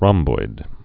(rŏmboid)